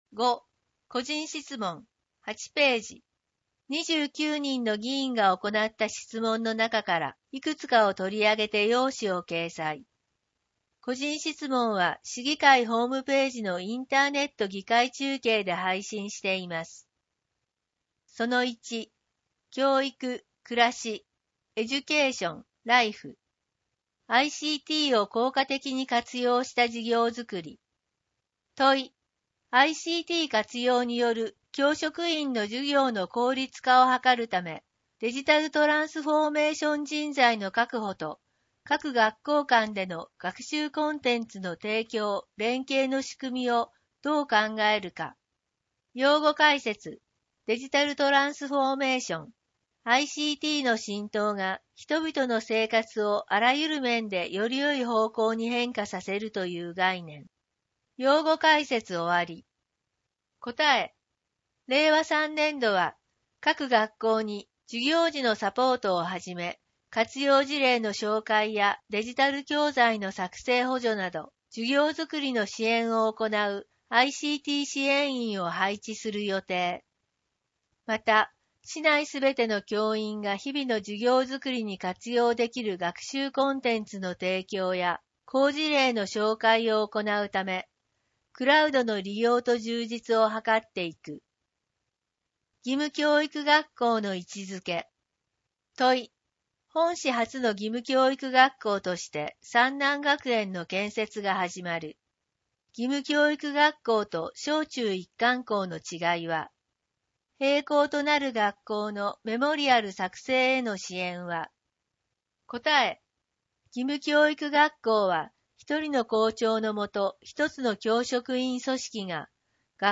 GIKAI-STYLE 岡山市議会だより11月号電子書籍版はこちらから 別ウィンドウで開く 声の市議会だより 「岡山市議会だより」を音声でお聞きいただけます。 声の市議会だより（音声版「岡山市議会だより」）は、ボランティア「岡山市立図書館朗読奉仕の会」のご協力により作成しています。